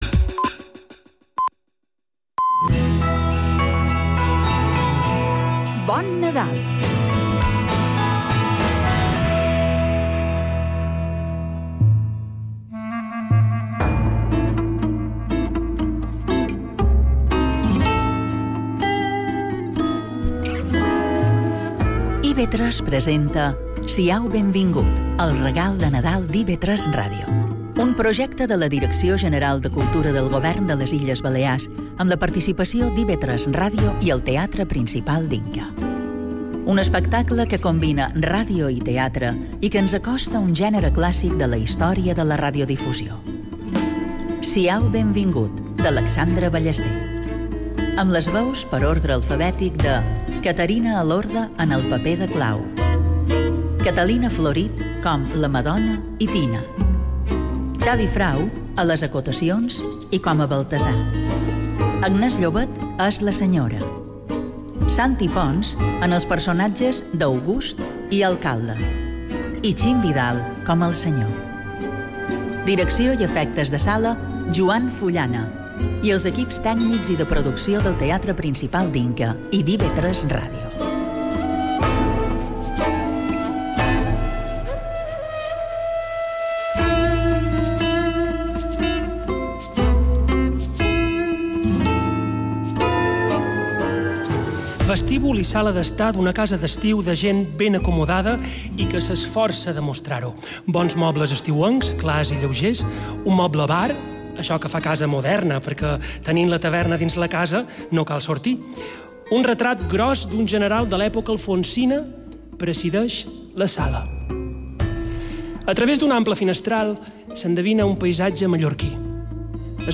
Aquest projecte combina ràdio i teatre i acosta al públic un gènere clàssic de la història de la radiodifusió.